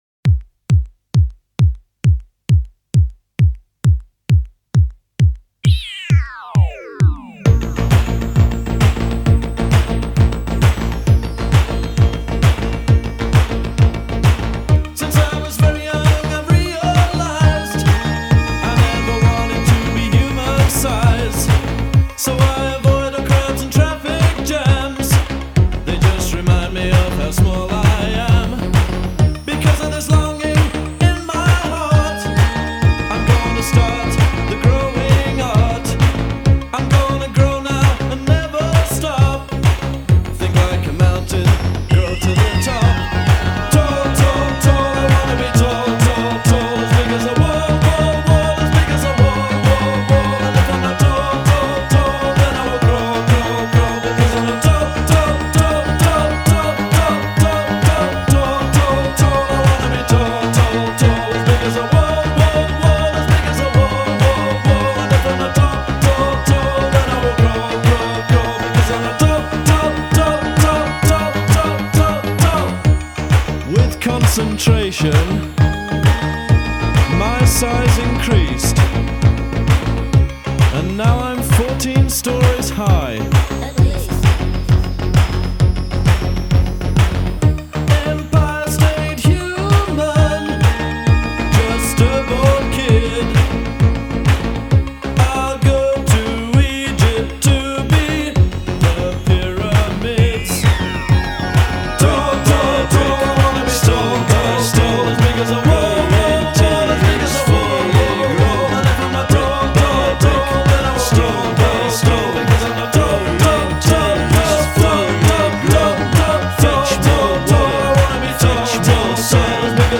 BPM131-132
Audio QualityPerfect (High Quality)